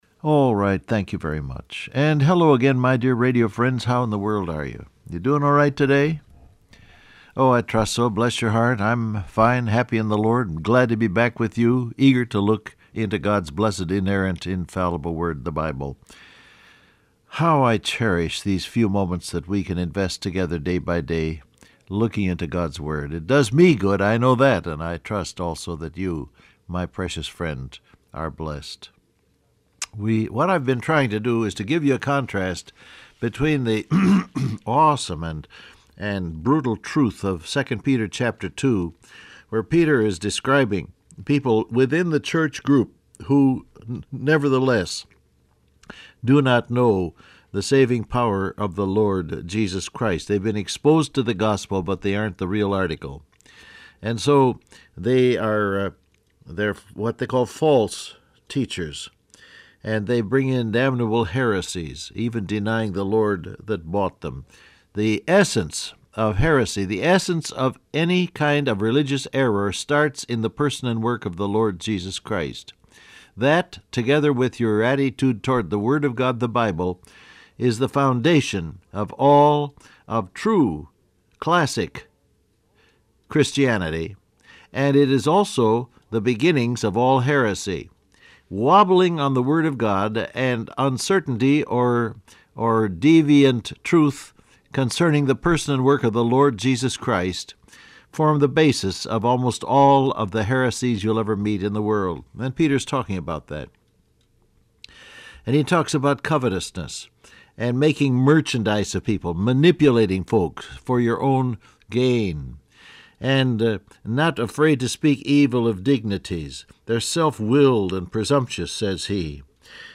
Download Audio Print Broadcast #7251 Scripture: 2 Peter 2 , 1 John 5:15, Romans 8:28 Topics: Word of God , God's Will , Loneliness Transcript Facebook Twitter WhatsApp Alright, thank you very much.